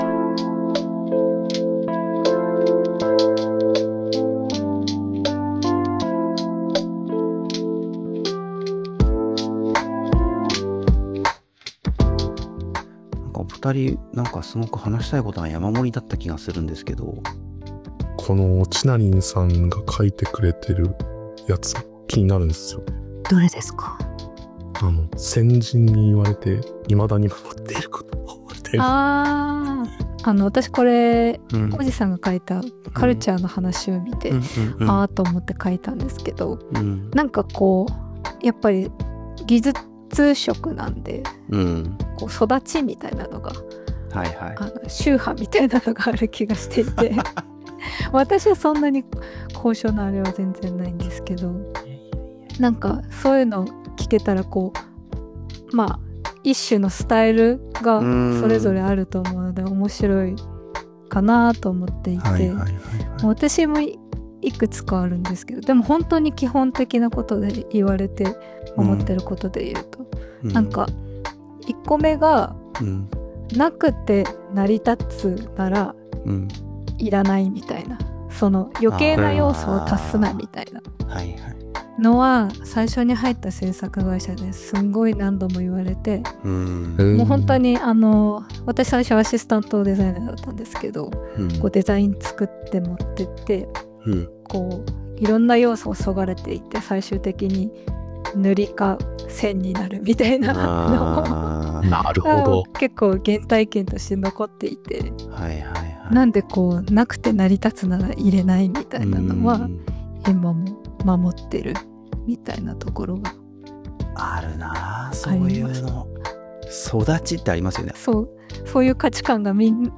ペパボのアートディレクター3人で雑談しました。